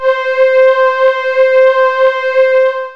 SMOOTH STRS.wav